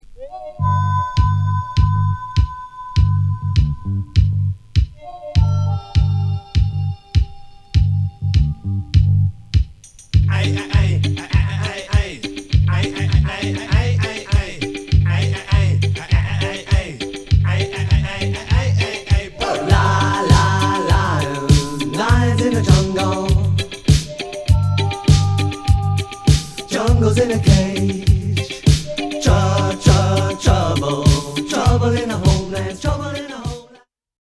ウェスタン・ムード、レゲー、そしてヒップホップ、エレクトロまでも
取り入れたサウンド・コラージュ、ごちゃ混ぜロック！！